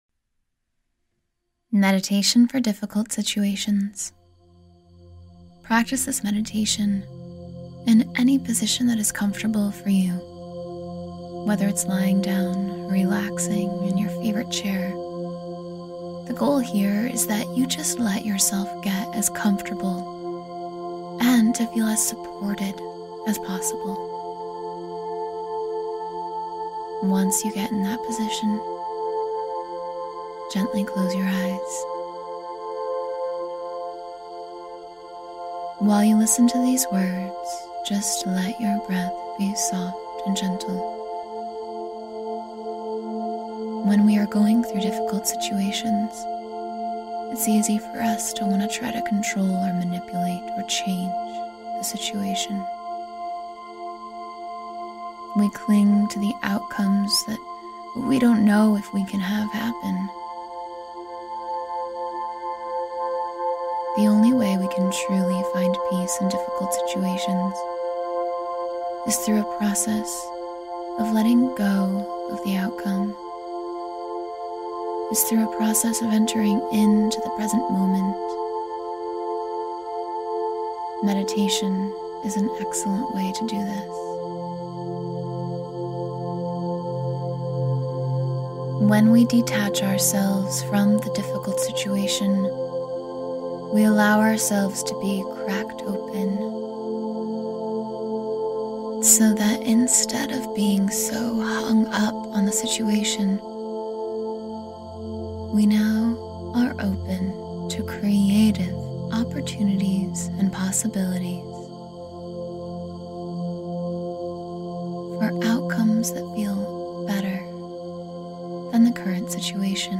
Guided Meditation to Move Through Tough Times with Strength